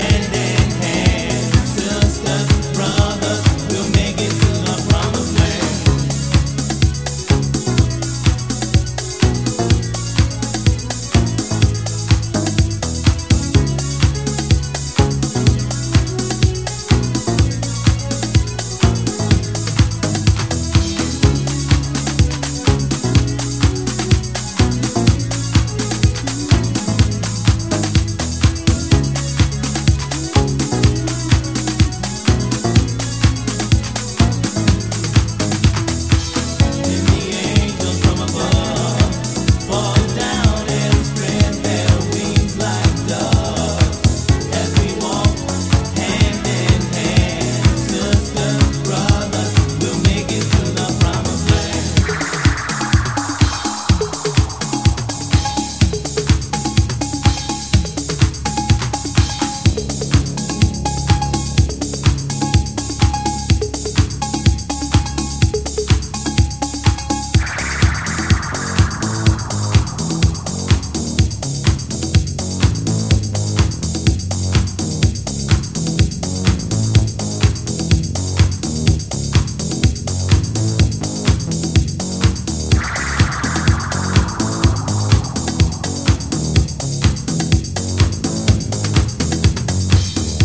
Classic Chicago house tune.